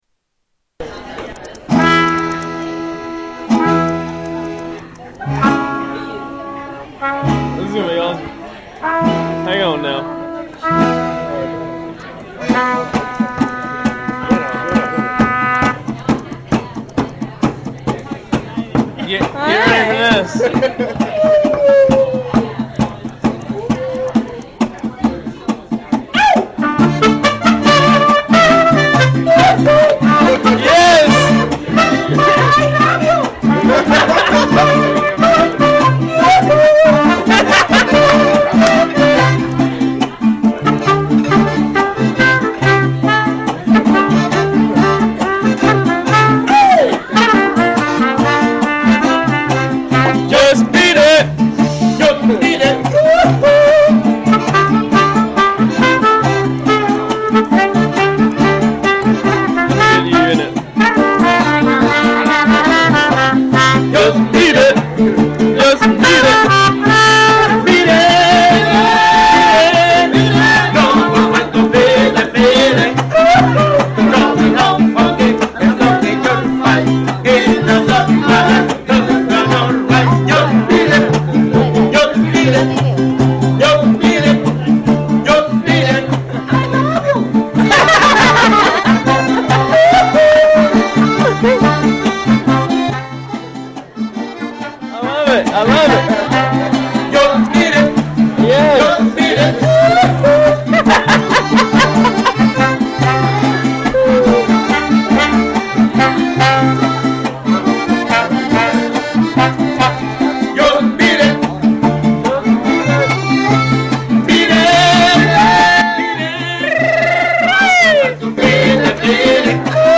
Mariachi Band